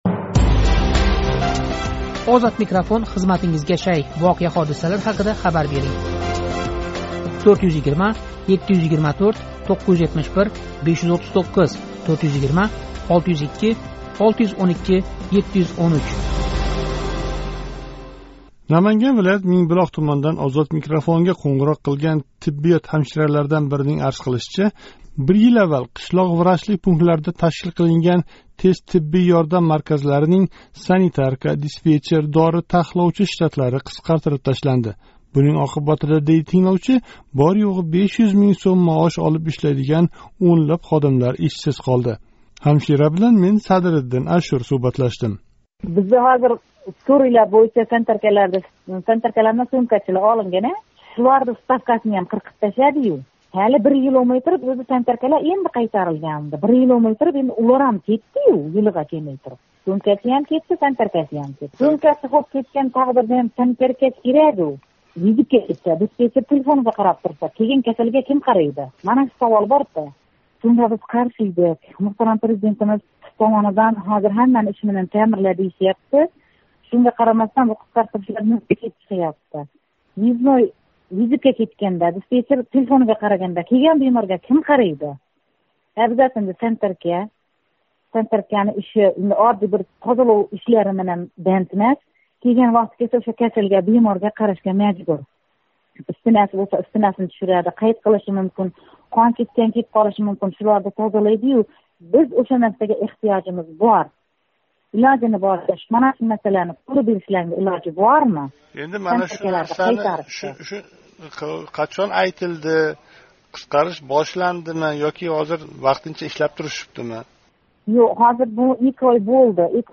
Наманган вилоят, Мингбулоқ туманидан OzodMikrofonга қўнғироқ қилган тиббиёт ҳамшираларидан бирининг арз қилишича, бир йил аввал қишлоқ врачлик пунктларида ташкил қилинган тез тиббий ёрдам марказларининг санитарка, диспетчер, дори тахловчи штатлари қисқартириб ташланди.